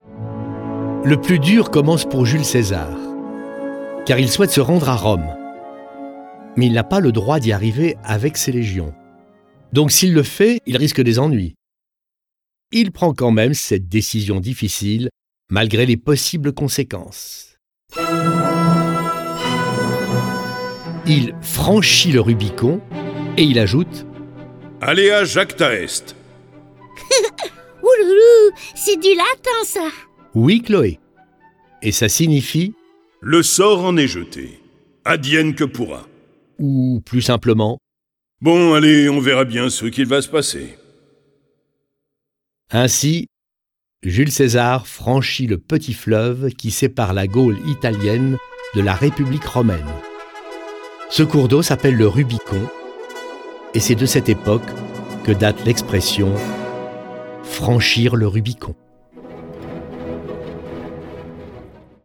Le récit de la vie du grand homme est animé par 6 voix et accompagné de plus de 30 morceaux de musique classique.
Le récit et les dialogues sont illustrés avec les musiques de Anerio, Bach, Beethoven, Bizet, Charpentier, Corelli, Debussy, Dvorak, Fauré, Grieg, Liszt, Locatelli, Marcello, Mendelssohn, Mozart, Pergolese, Rachmaninov, Rameau, Rimski-Korsakov, Rossini, Saint-Saëns, J. Strauss, Tchaikovski, Vivaldi et Wagner.